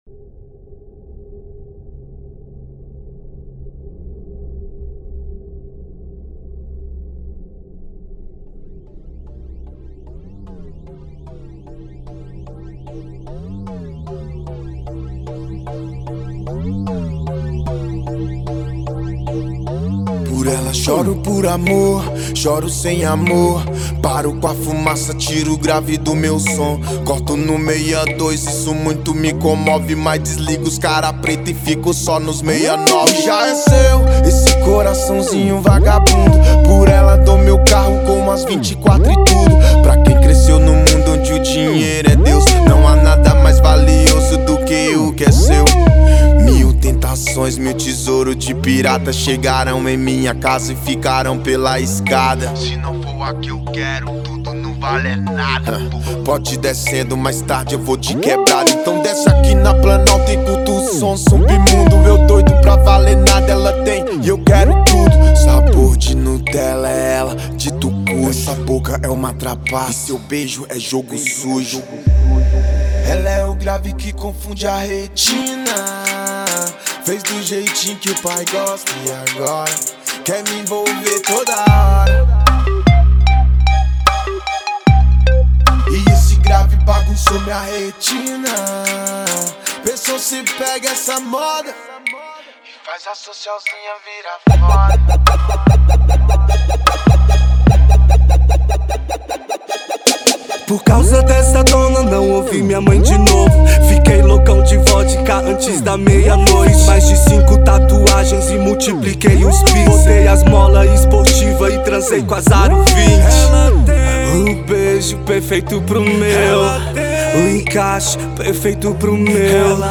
2025-02-23 14:58:16 Gênero: Hip Hop Views